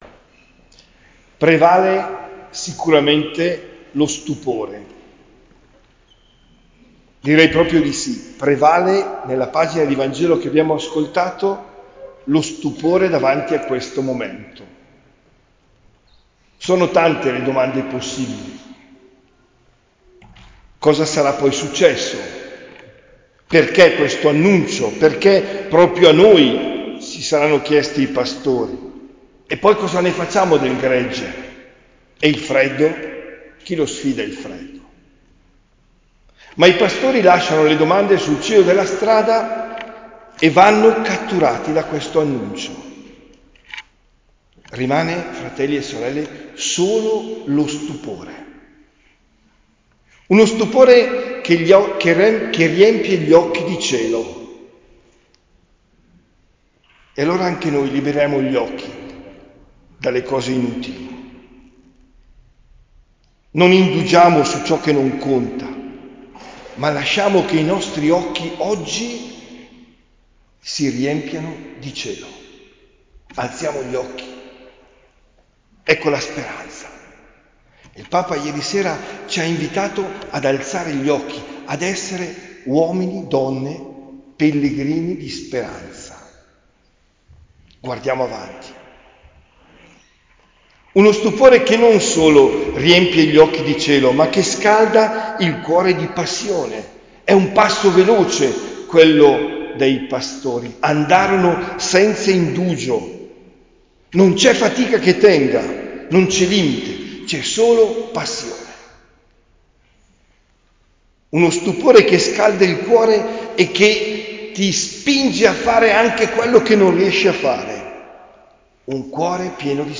OMELIA DEL 25 DICEMBRE 2024
omelia-natale-2024.mp3